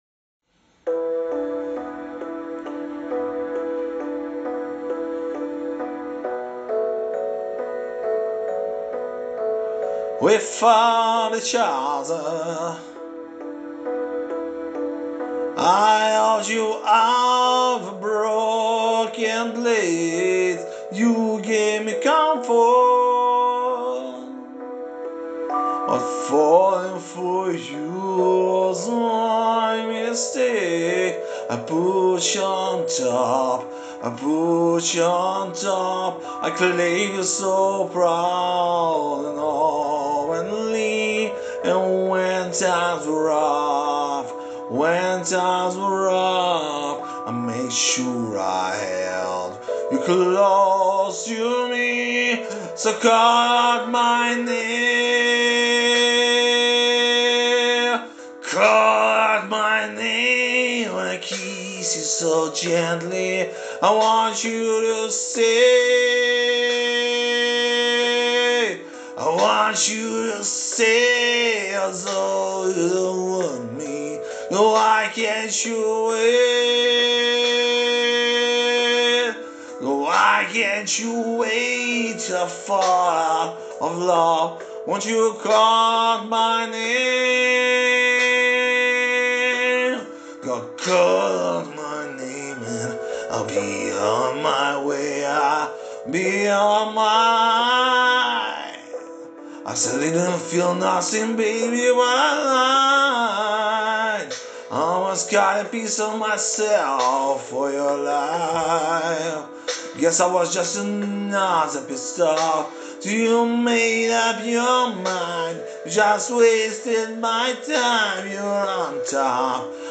ТИП: Пісня
СТИЛЬОВІ ЖАНРИ: Романтичний